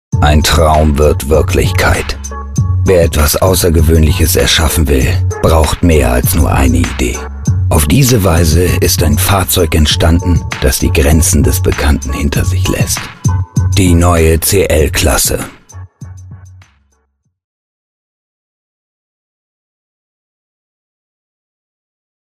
Stimme mit Wiedererkennungswert, großer Flexibilität und Zuverlässigkeit.
Sprechprobe: Werbung (Muttersprache):